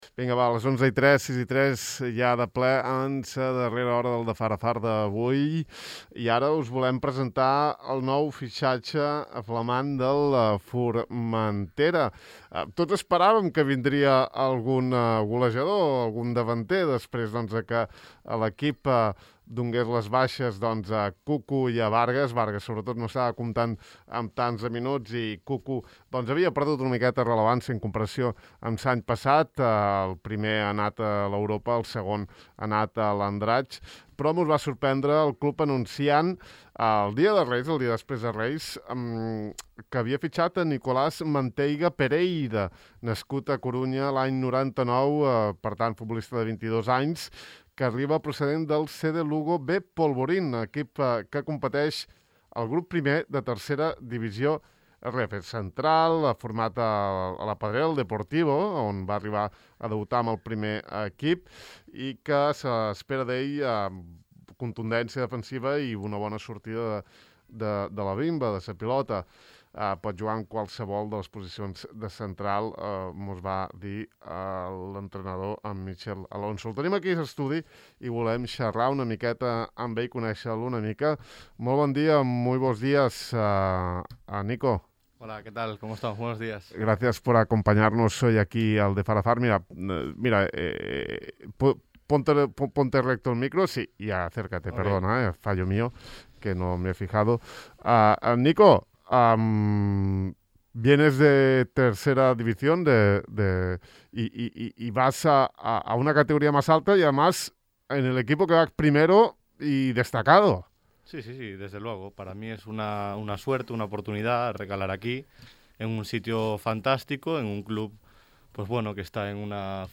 Ahir vam xerrar amb ell al De far a far.